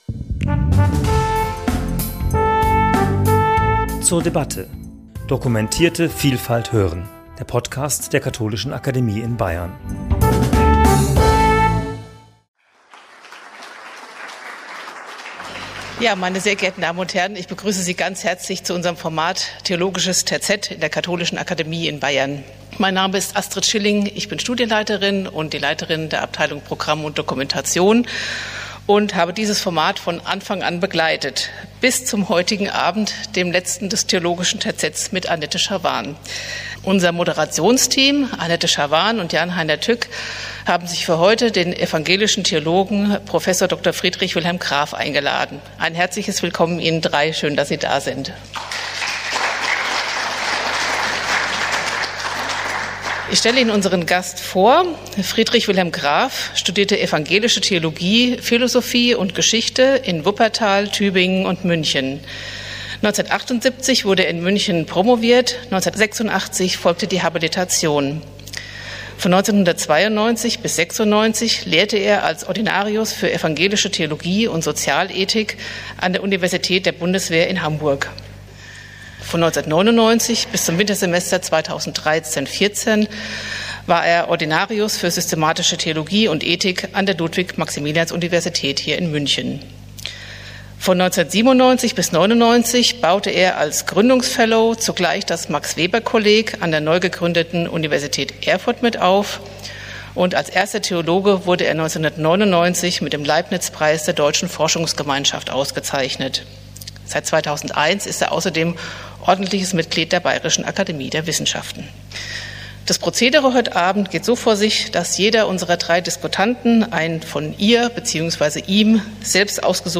Das Gespräch über Werke von Aleida und Jan Assmann, Jan Loffeld und Anselm Schubert fand am 27. November 2024 in der Katholischen Akademie in Bayern statt.